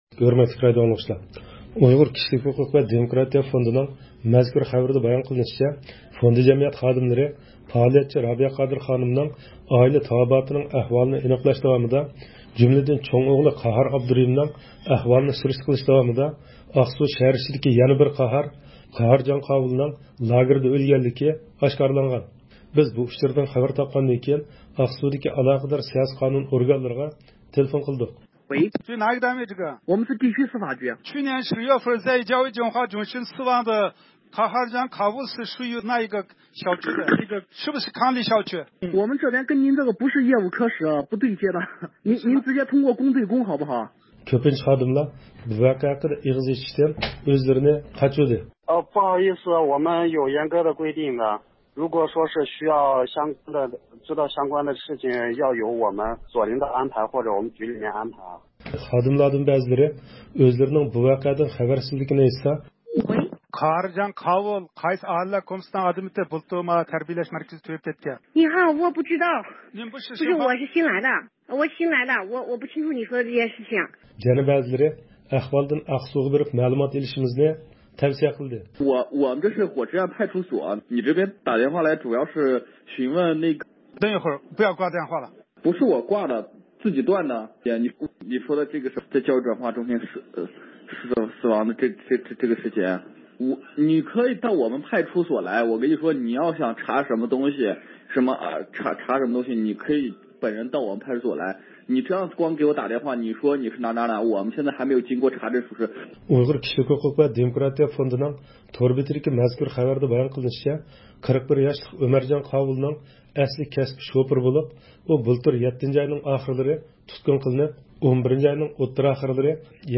مۇخبىرىمىزنىڭ ئاقسۇغا قارىتا ئېلىپ بارغان تېلېفون زىيارەتلىرى داۋامىدا مەزكۇر ئۇچۇرنىڭ توغرىلىقى دەلىللەندى.
بىز بۇ ئۇچۇردىن خەۋەر تاپقاندىن كېيىن ئاقسۇدىكى ئالاقىدار سىياسىي-قانۇن ئورگانلىرىغا تېلېفون قىلدۇق.